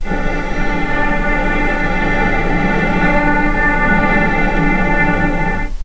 vc_sp-D4-pp.AIF